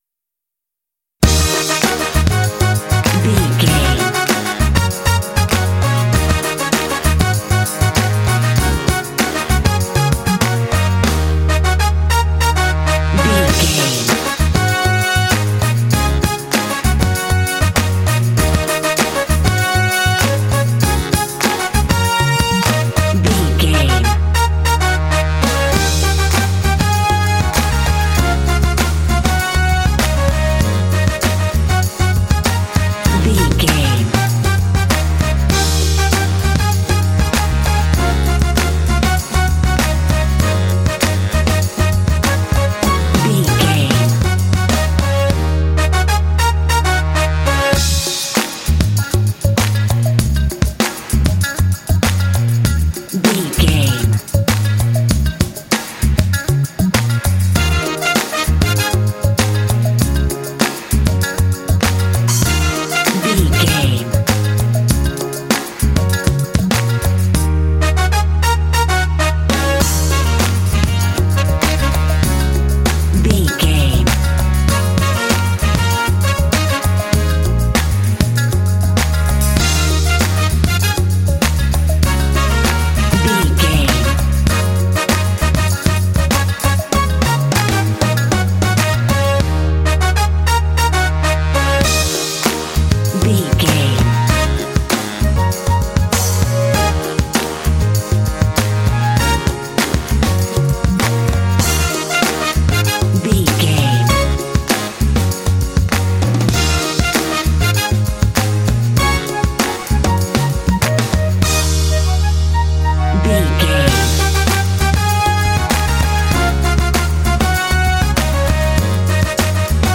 Uplifting
Aeolian/Minor
E♭
smooth
groovy
driving
drums
percussion
bass guitar
brass
piano
soul
motown